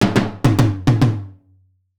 Wake Up Tom Fill.wav